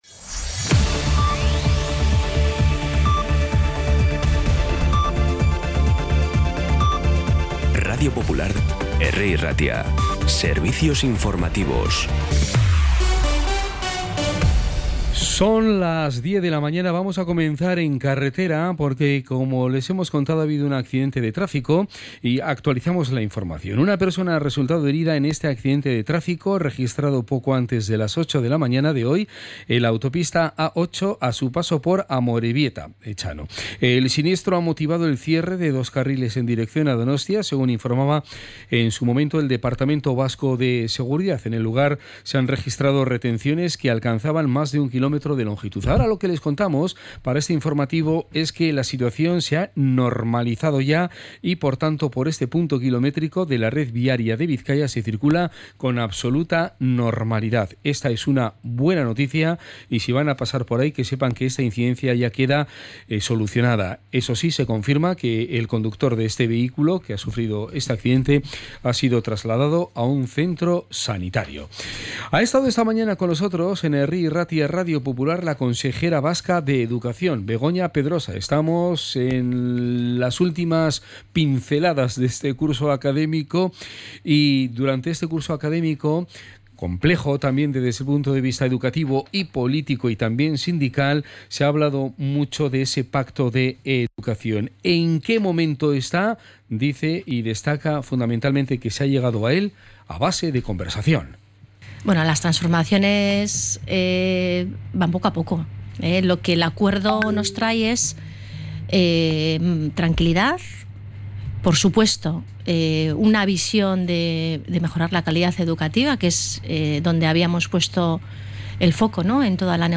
Información y actualidad desde las 10 h de la mañana